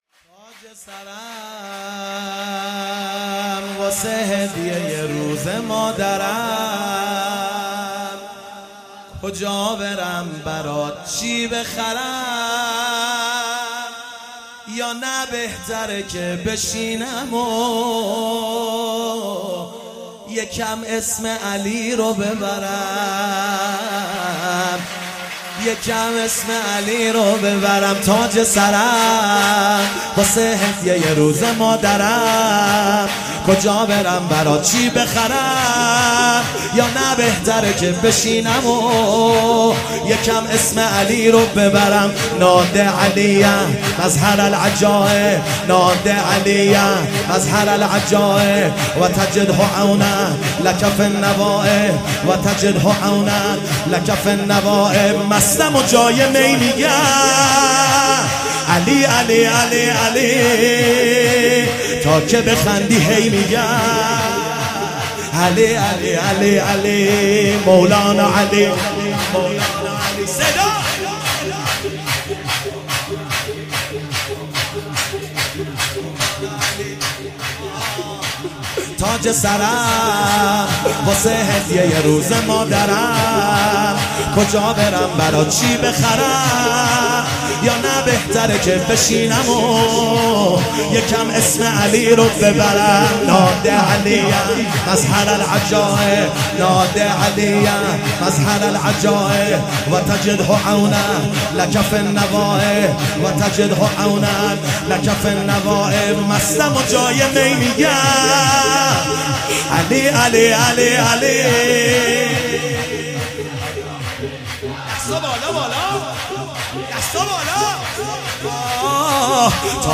ولادت حضرت زهرا(س)97 - شور - تاج سرم